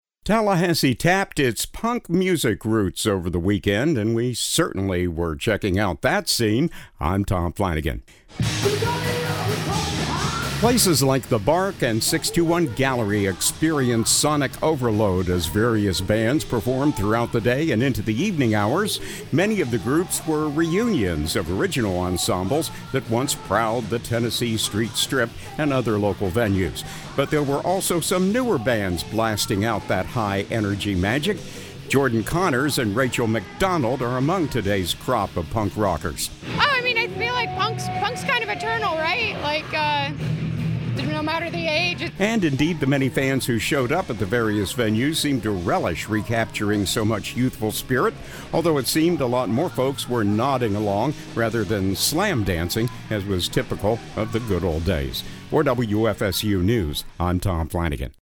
Tallahassee tapped its punk music roots over the weekend during the Pioneers of Punk exhibit and celebration.
Places like The Bark and 621 Gallery experienced sonic overload as various bands performed throughout the day and into the evening hours.
But there were also some newer bands blasting out that high-energy magic.